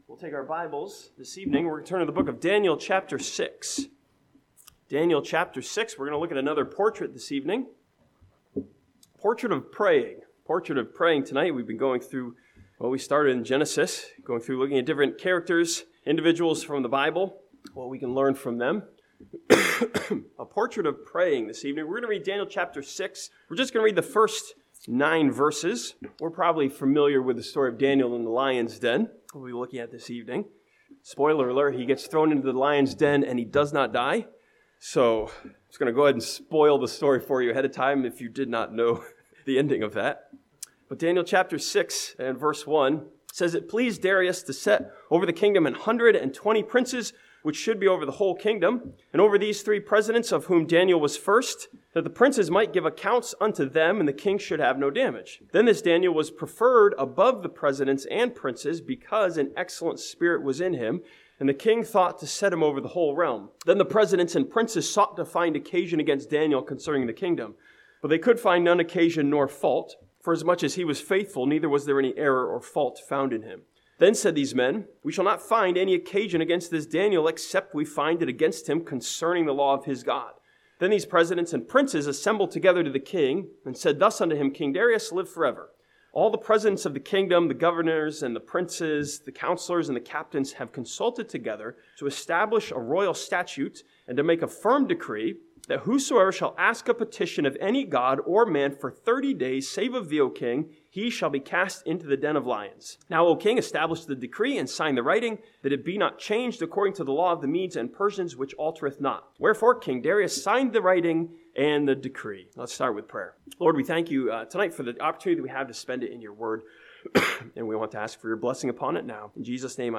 This sermon from Daniel chapter 6 studies Daniel as a portrait of prayer when he chooses to pray to God and not the king.